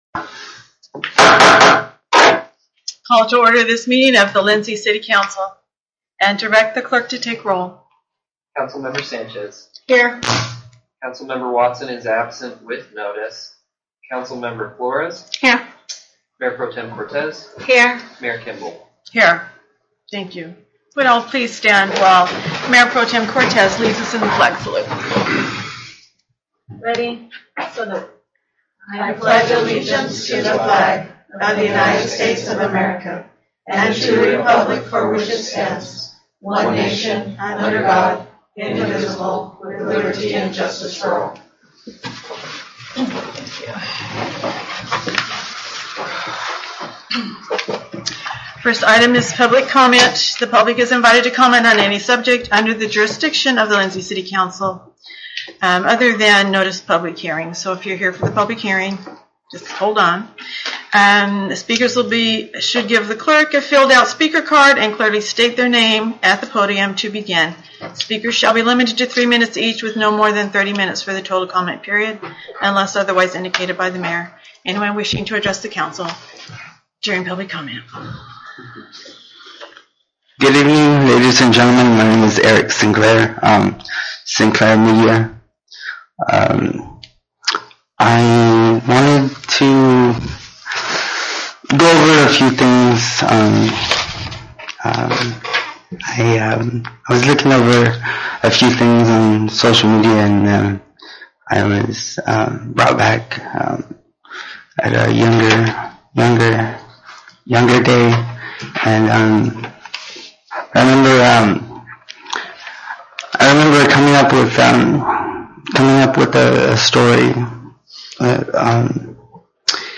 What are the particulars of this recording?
City Council Meeting